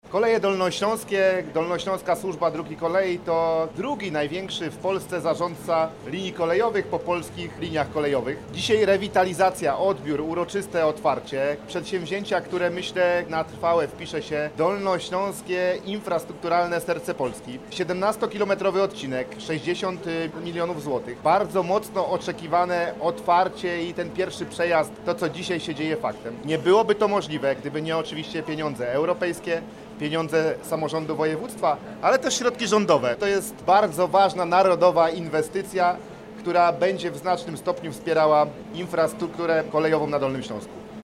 Odbudowujemy kolejne linie, przywracamy kolej w miejsca, gdzie kiedyś była codziennością, walczymy z wykluczeniem komunikacyjnym i udowadniamy, że Dolny Śląsk jest infrastrukturalnym sercem Polski – mówi Paweł Gancarz, Marszałek Województwa Dolnośląskiego.